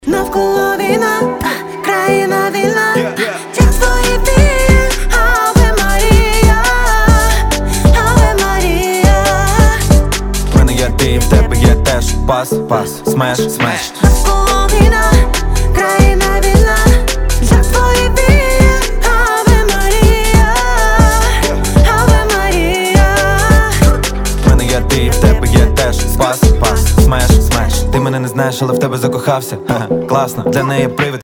• Качество: 320, Stereo
дуэт
RnB